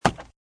woodwood3.mp3